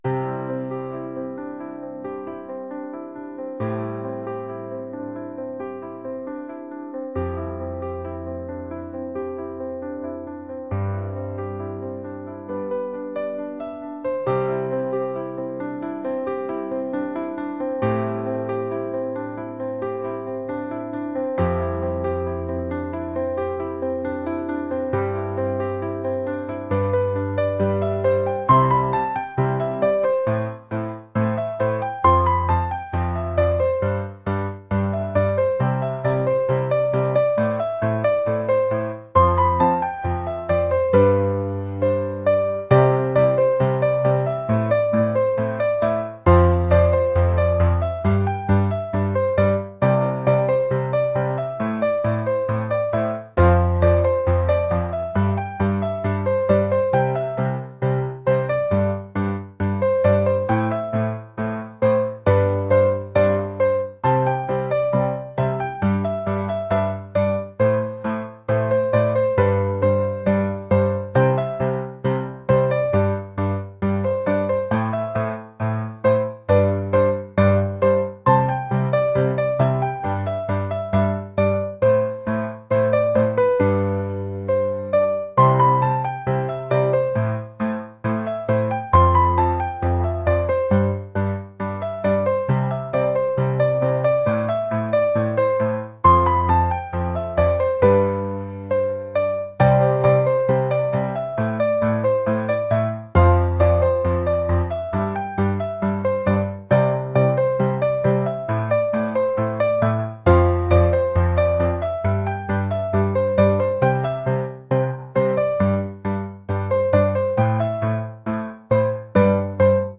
pop | acoustic | romantic